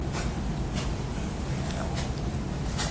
The crackling or noise you hear was the team walking.
Additionally when you hear 2 chimes that is the sound of the hand held camcorder(s) being turned on and off.
Sounds like ‘Now Get Ready’